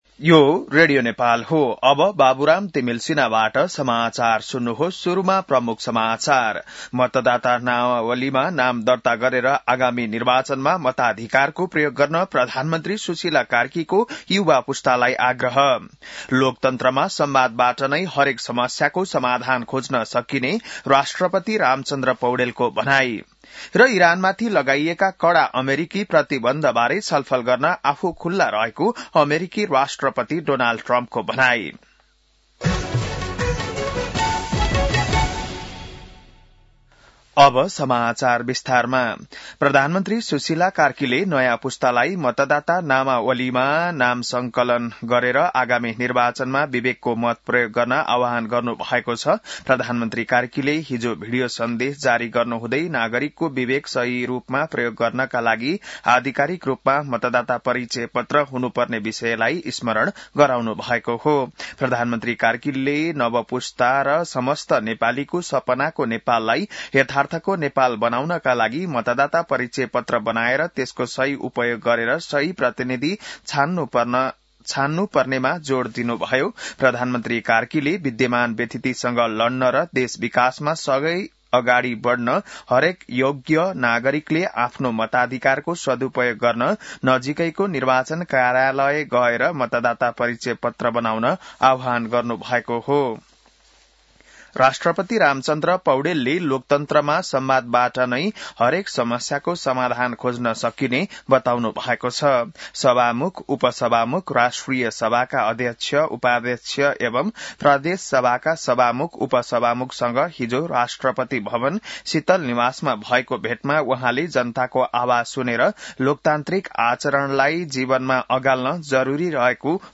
बिहान ९ बजेको नेपाली समाचार : २२ कार्तिक , २०८२